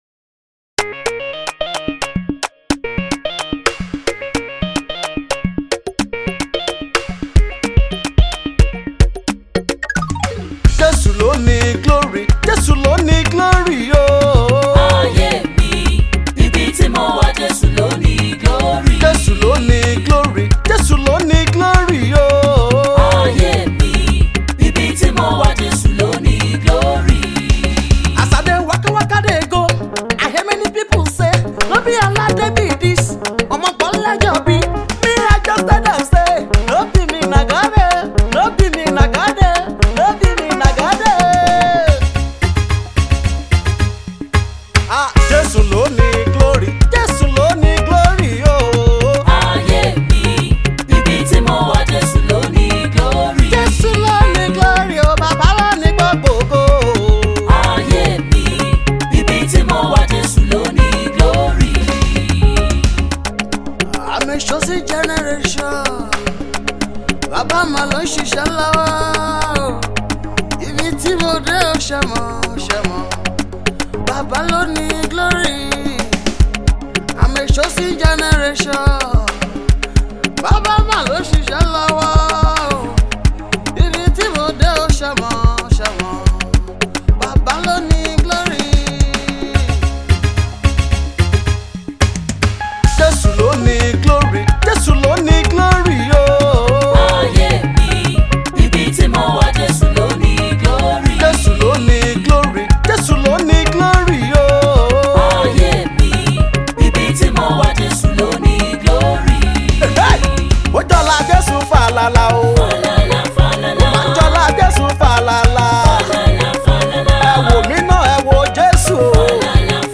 praise song
gospel